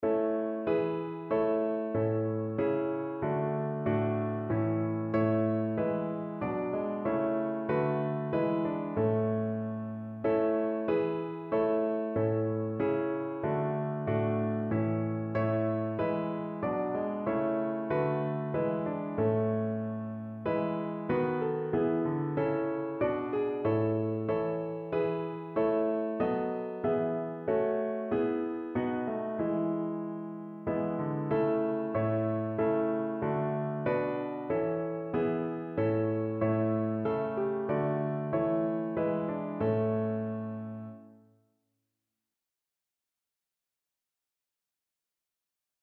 Notensatz (4 Stimmen gemischt)